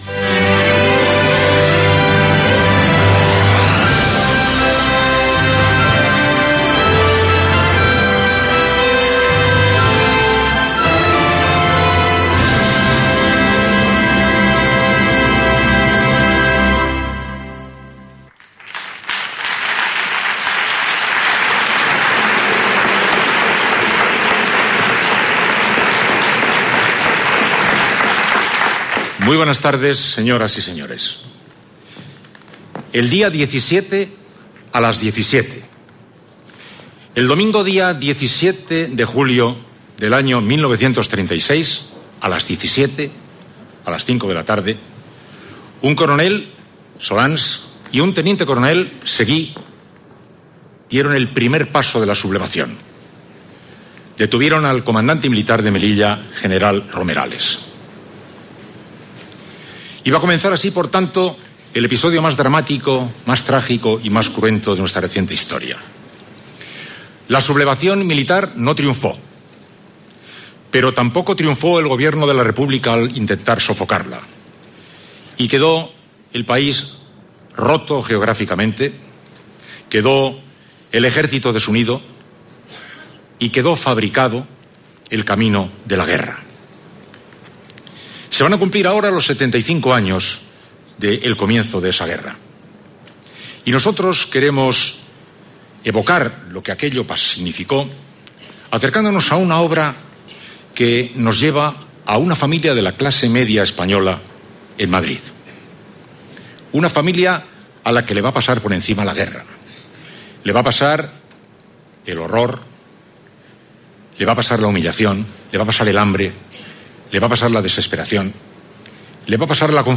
Amb motiu del 75è aniversari de la guerra civil espanyola, emissió en directe i cara al públic de l'obra de Fernando Fernán Gómez.
Sintonia institucional de la Cadena SER, introducció d'Iñaki Gabilondo i primers moments de l'obra
Ficció